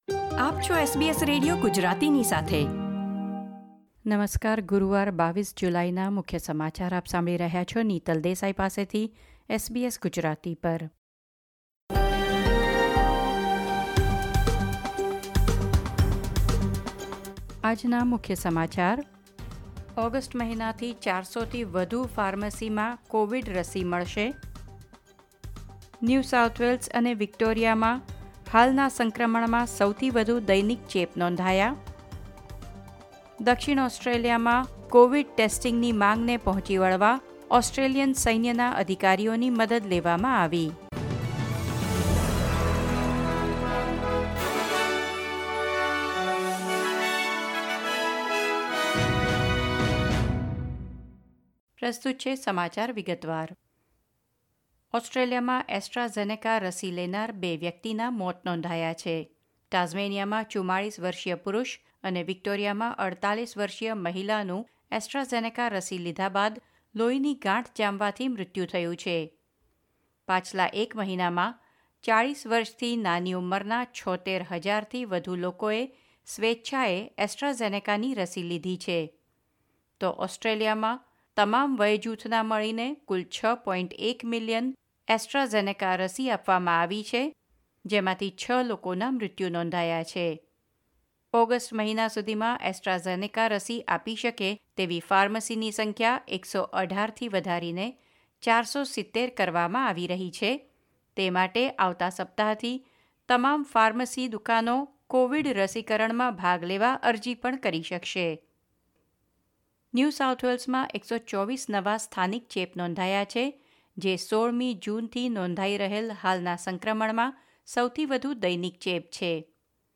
SBS Gujarati News Bulletin 22 July 2021